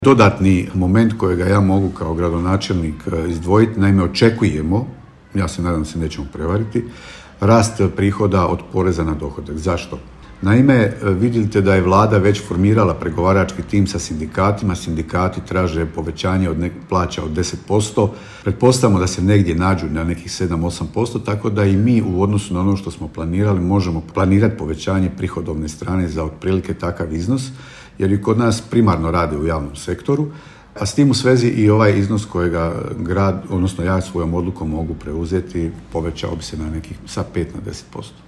Kazao je gradonačelnik te dodao: